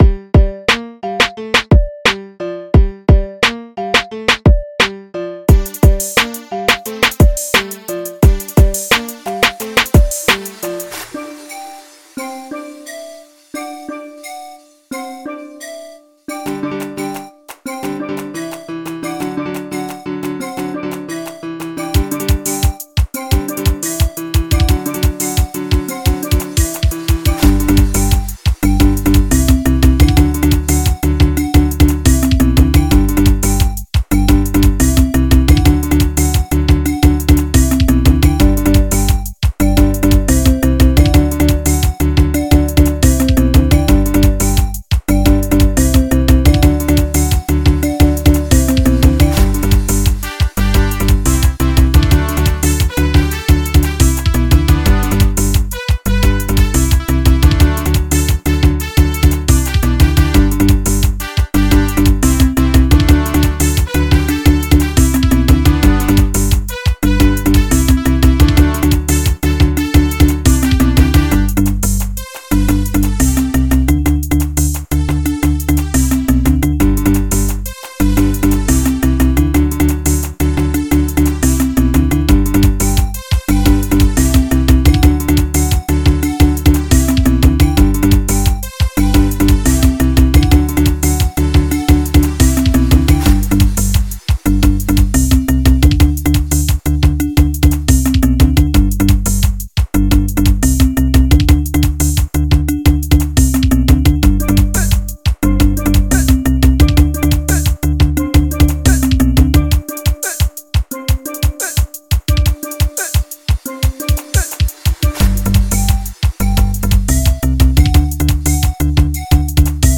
03:36 Genre : Xitsonga Size